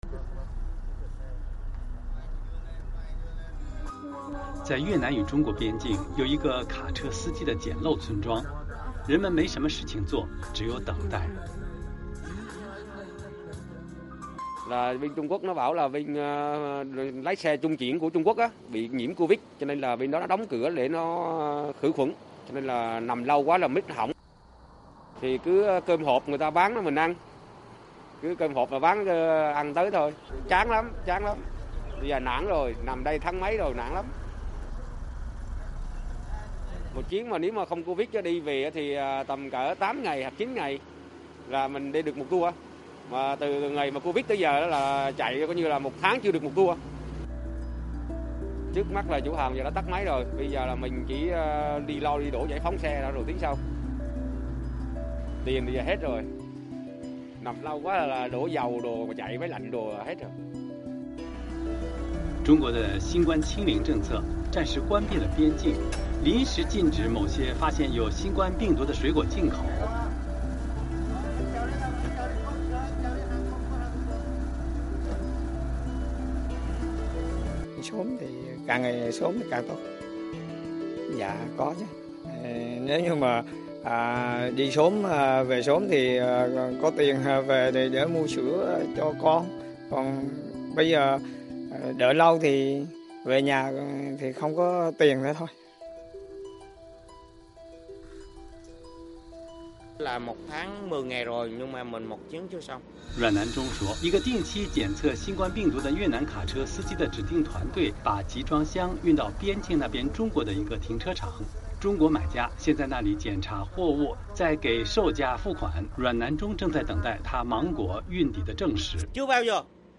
美国之音在东南亚的电视记者过去几个月来对农民和卡车司机等相关人士进行了交谈。这是他们的报道。